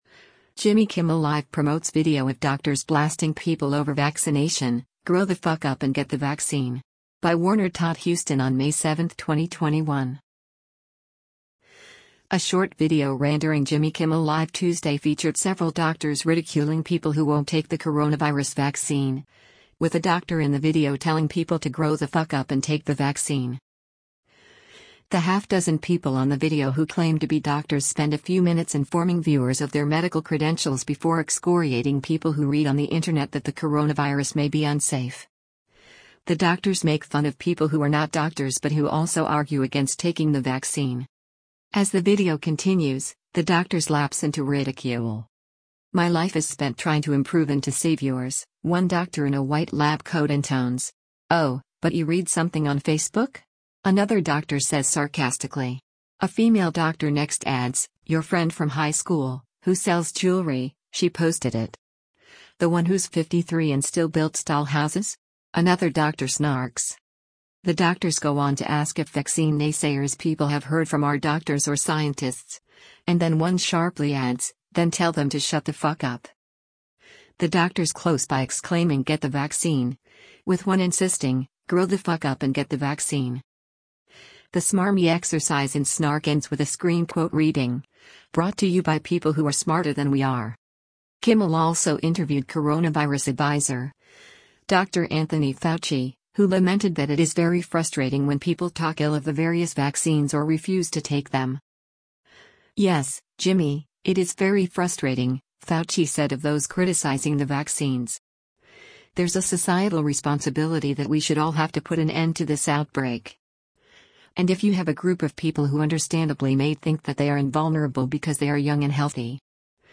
A short video ran during Jimmy Kimmel Live Tuesday featured several doctors ridiculing people who won’t take the coronavirus vaccine, with a doctor in the video telling people to “Grow the fuck up and take the vaccine.”
As the video continues, the doctors lapse into ridicule.
“Oh, but you read something on Facebook?” another doctor says sarcastically.
The doctors close by exclaiming “get the vaccine,” with one insisting, “grow the fuck up and get the vaccine.”